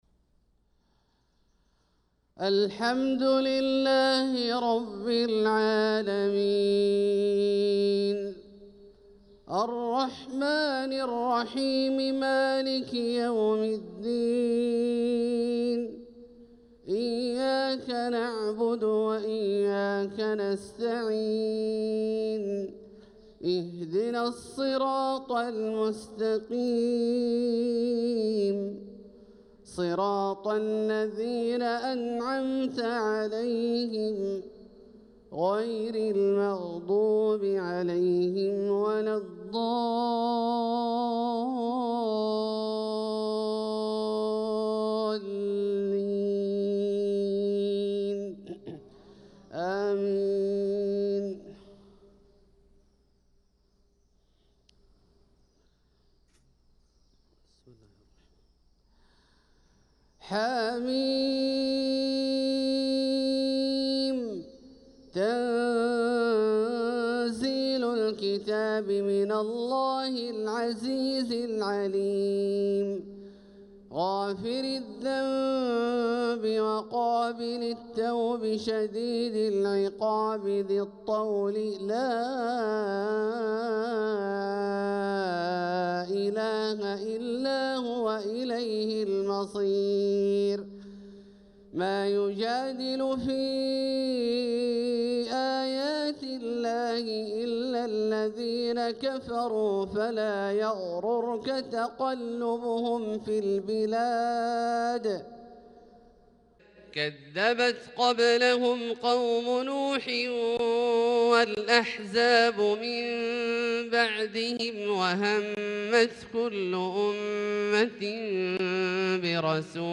صلاة الفجر للقارئ عبدالله الجهني 7 صفر 1446 هـ
تِلَاوَات الْحَرَمَيْن .